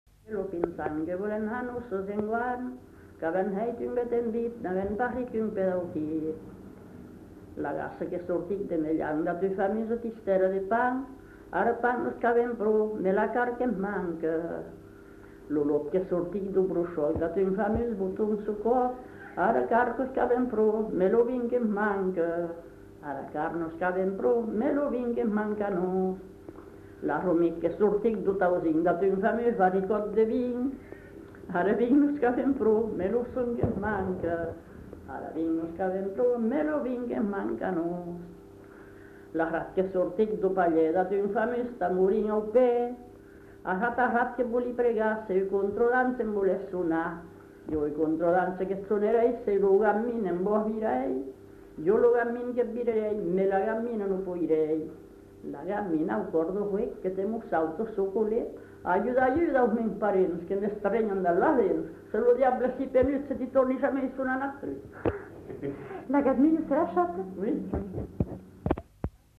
[Brocas. Groupe folklorique] (interprète)
Genre : chant
Effectif : 1
Type de voix : voix de femme
Production du son : chanté